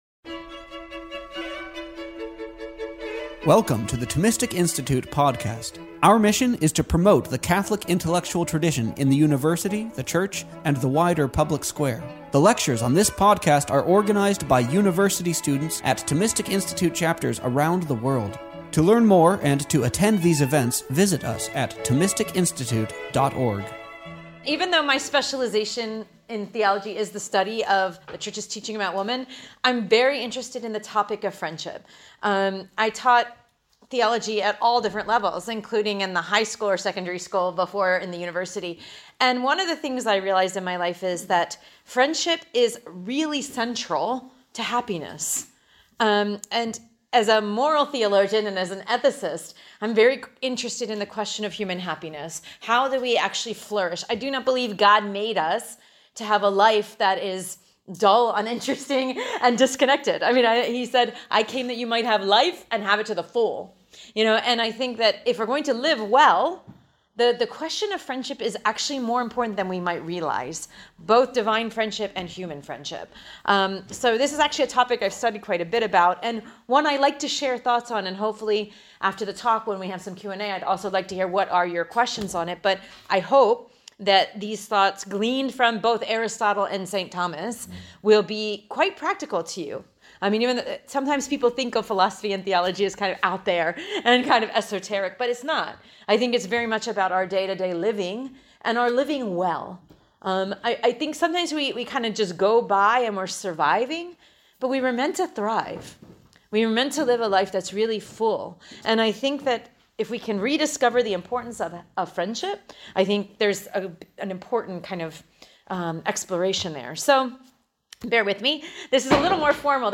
This lecture was given on November 27th, 2025, at Thomistic Institute in Limerick.